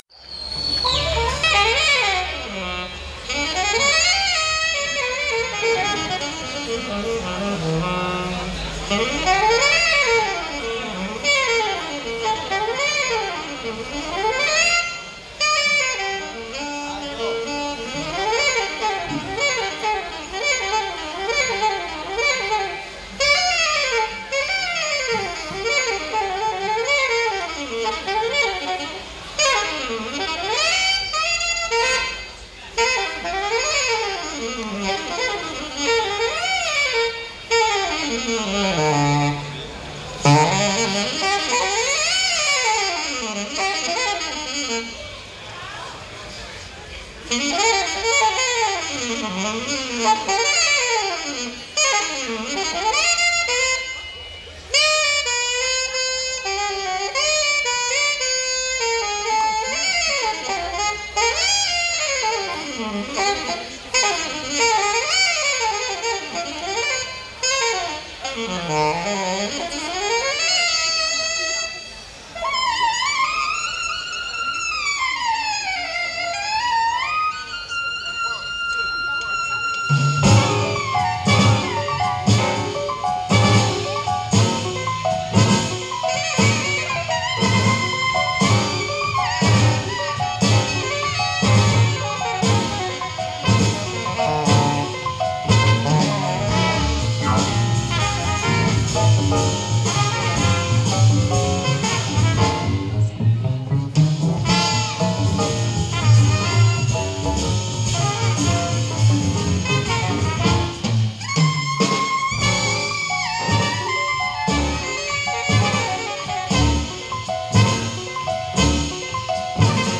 Registrazione privata (mics)